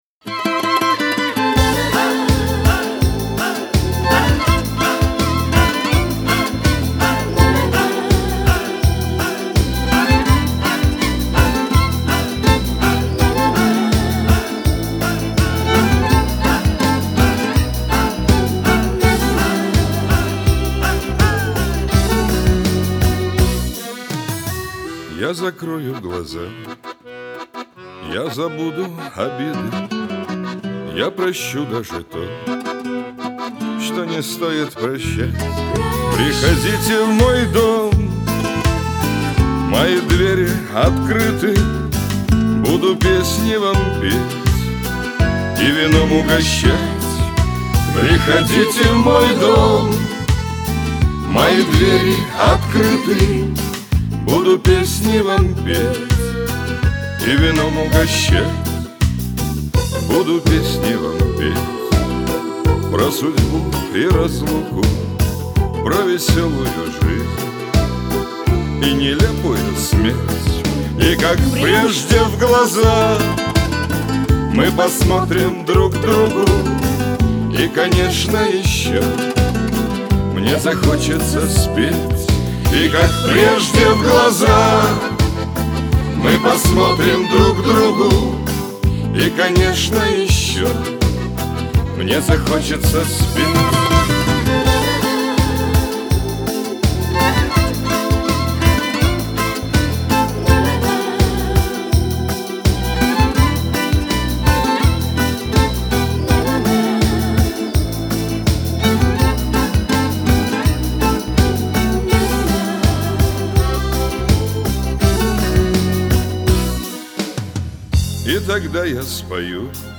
Получился прекрасный дуэт.